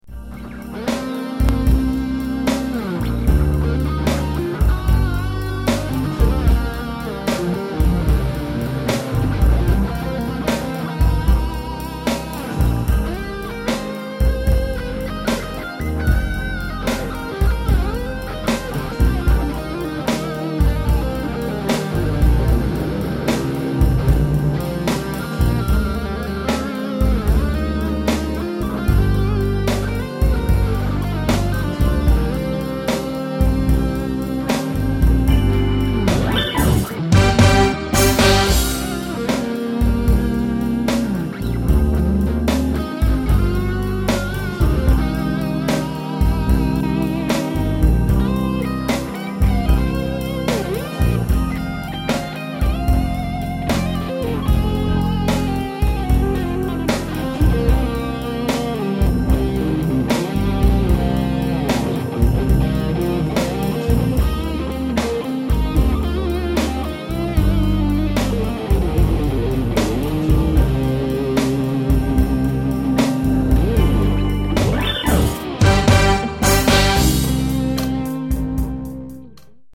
Donc j'ai fait quelque chose d'assez sobre et rapide, one shot un son pour les blues.
Vox tonelab SE pour le reste audacity mais j'ai rien retravaillé, c'est du pur pur !
Blues Vigier
Je trouve que les guitares sont un peu loin dans le mix.
blues-essai-mixrapide-vigier.mp3